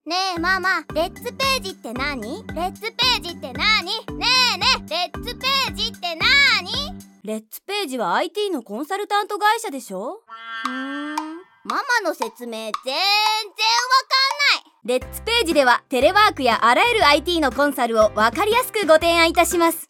今回は自社でラジオCMを作成しました。
【ラジオCM 1】ITコンサルタント編（20秒）